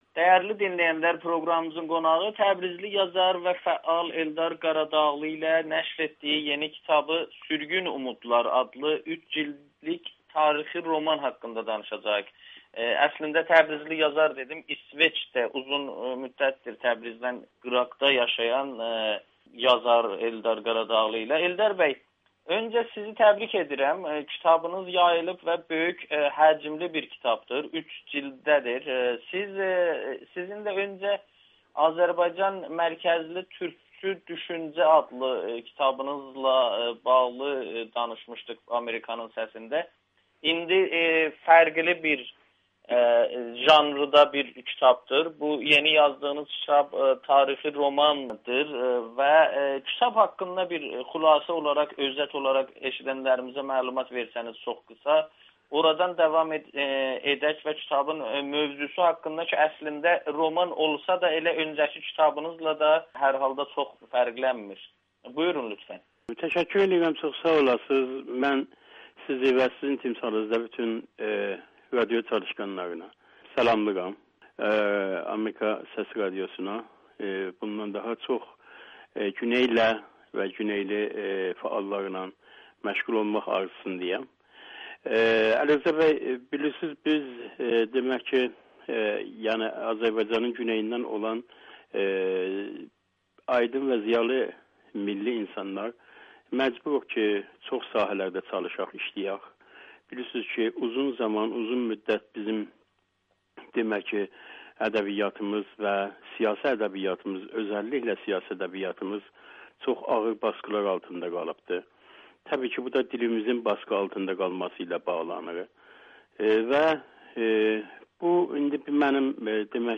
Sürgün Umudlar: İran Azərbaycanında kütləvi sürgün hekayəsi [Audio-Müsahibə]